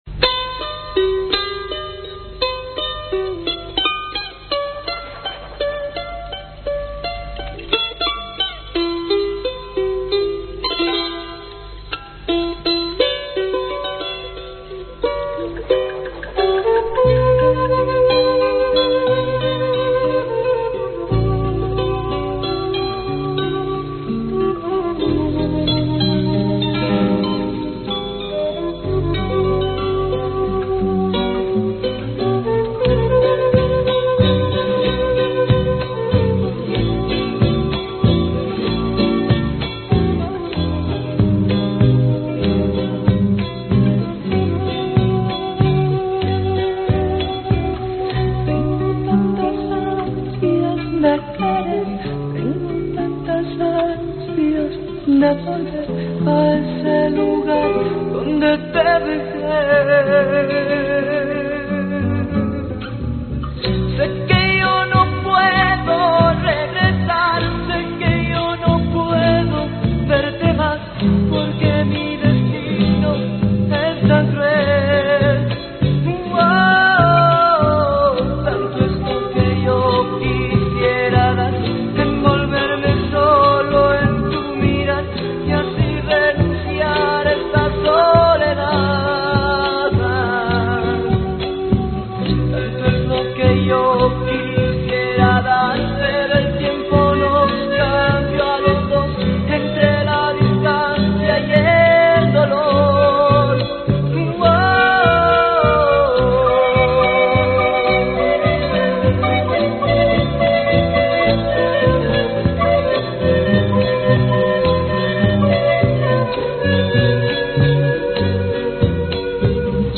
Zampoņas
Percusion
Voz,Guitarra,Charango
Vientos
Bateria,Efectos